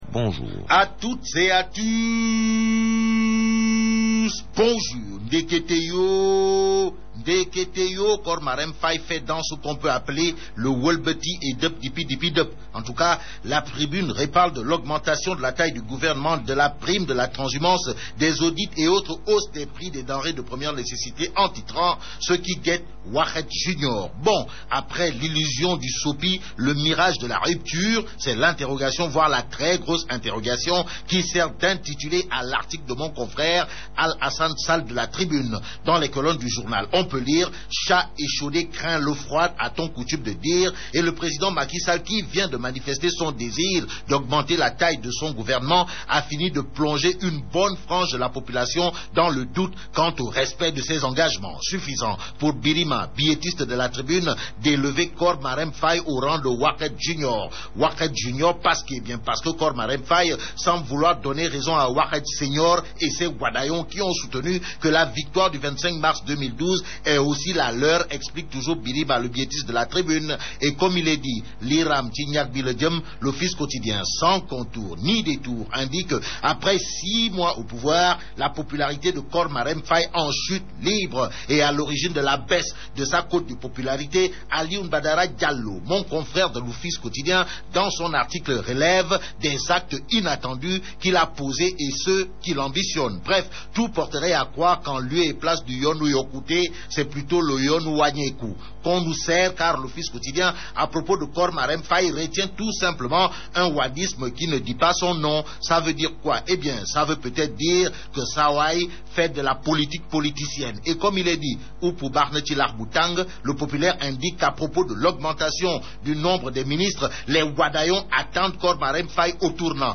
Revue de presse du 02 octobre 2012